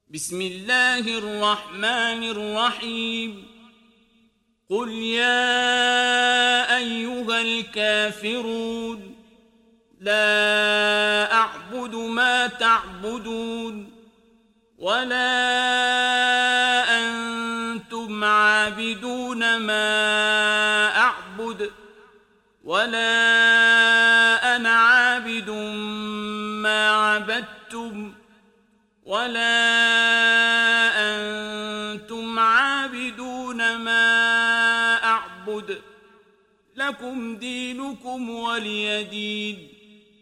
Kafirun Suresi mp3 İndir Abdul Basit Abd Alsamad (Riwayat Hafs)